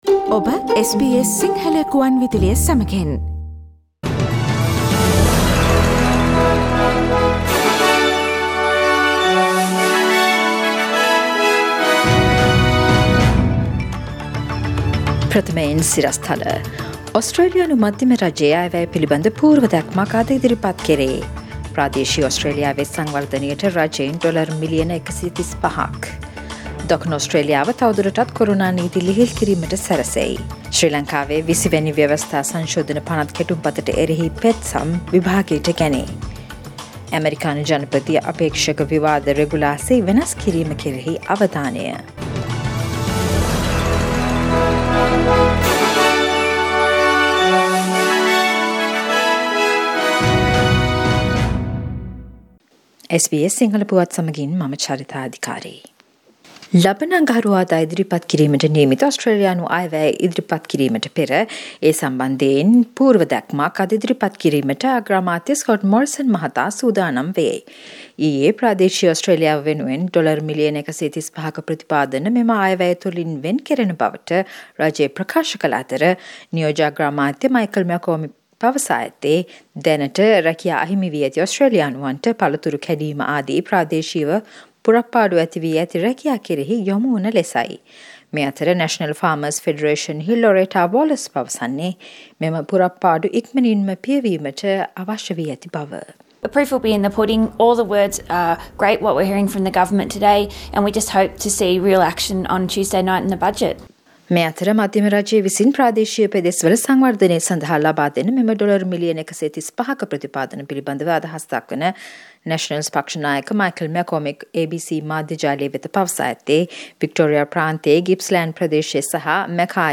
Daily News bulletin of SBS Sinhala Service: Thursday 1st of October 2020